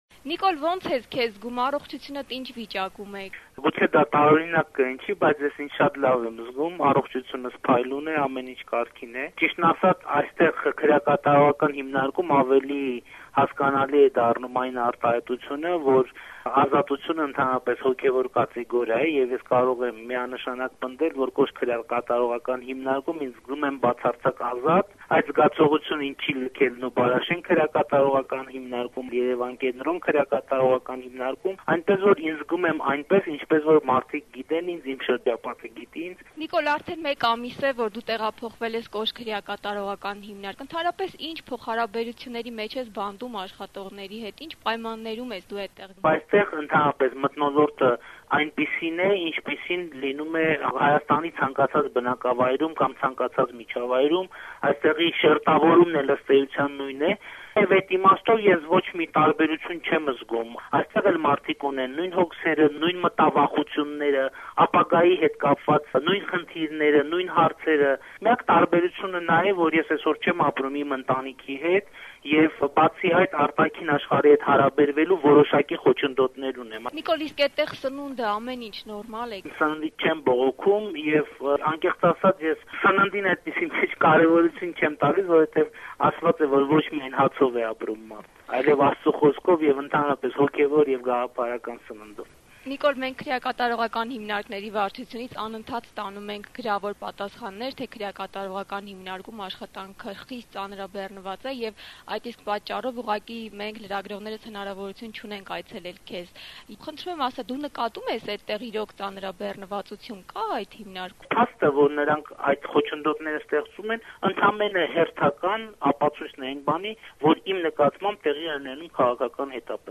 Интервью с Николом Пашиняном